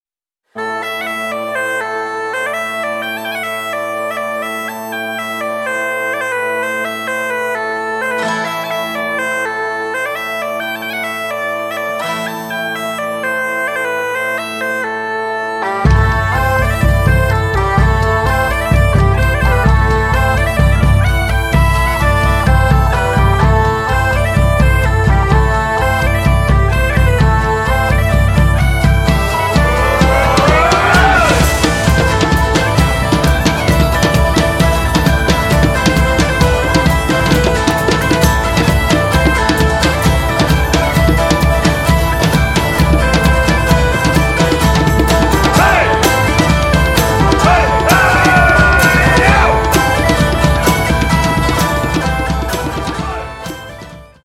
Středověká hudba